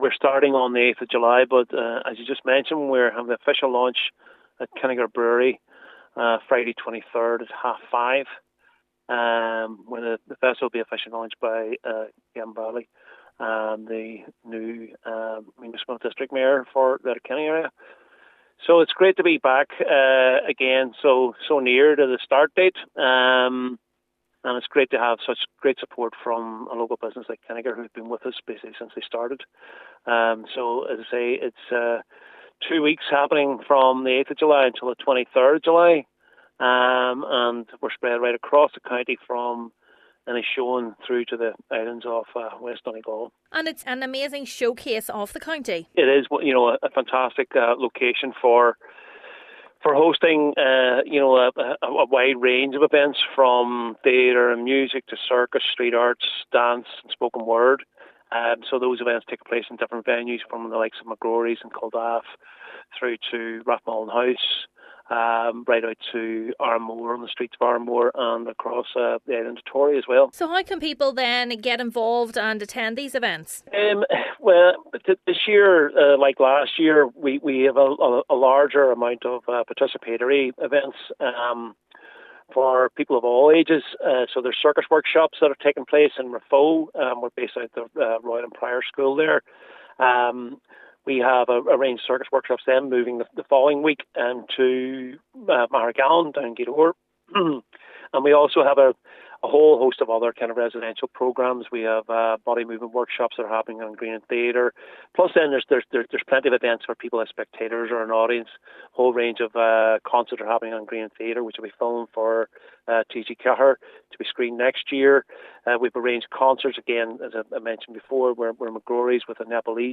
Speaking ahead of this evening’s launch at Kinnegar Brewery in Letterkenny,